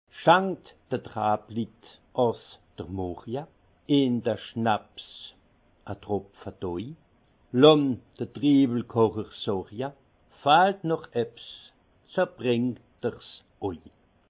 Haut Rhin
Munster